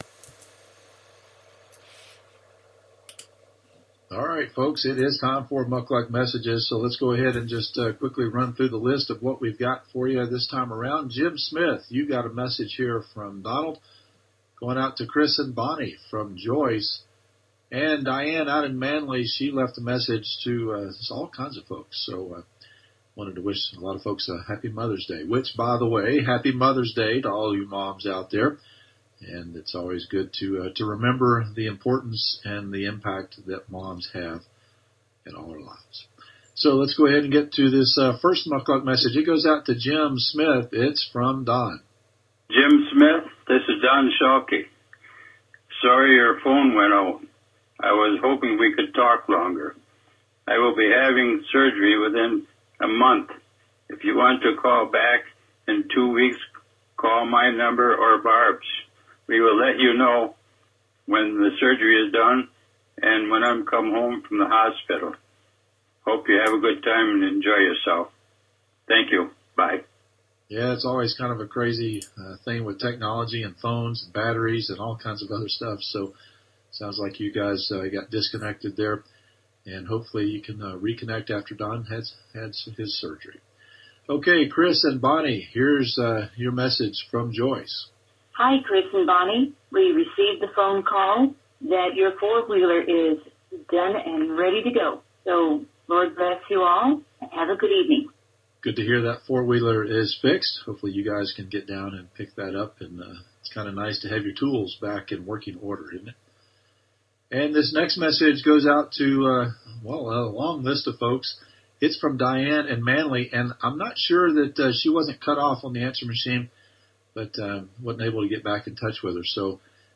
Mukluks are like eavesdropping on the home answering machines of strangers. If you know anyone who’s out in the bush without two-way communication and you have something you want to say to them, you can call, email, or text the radio station and leave a message that will be broadcast for the entire listening audience to hear.